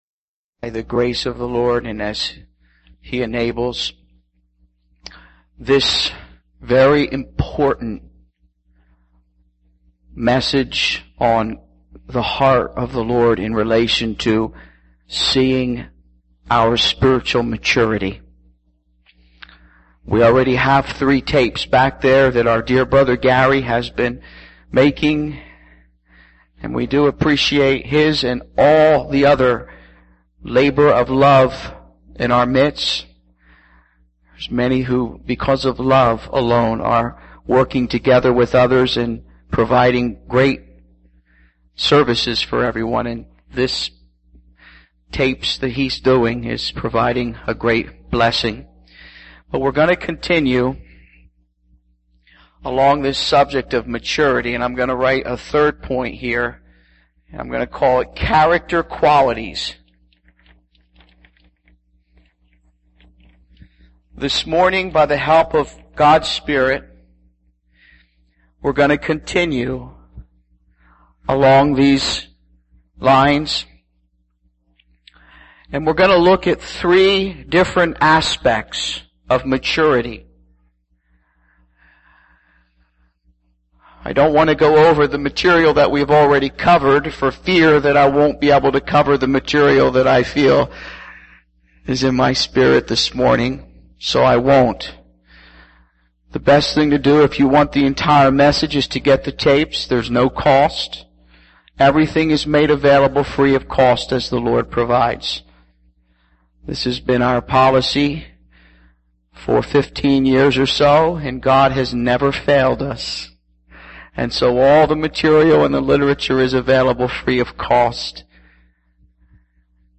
SermonIndex